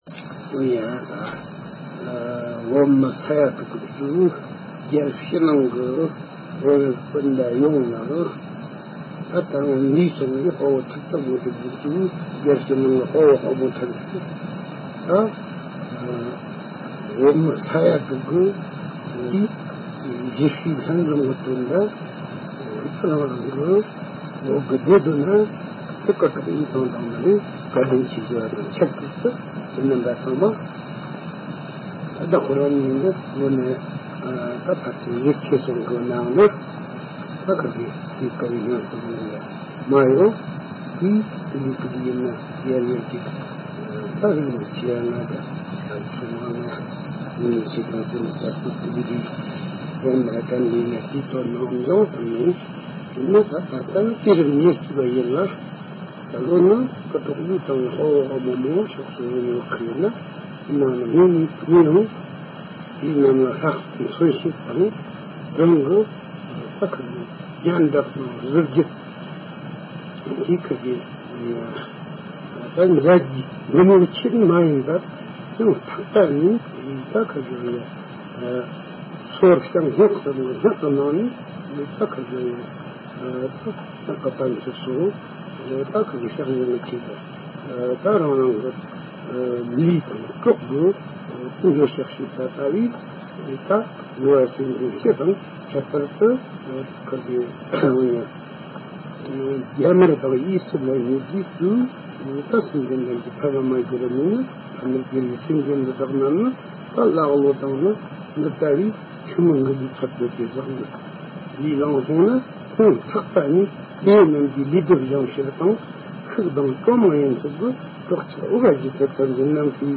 བོད་ནས་འབྱོར་བ།
སྒྲ་ལྡན་གསར་འགྱུར།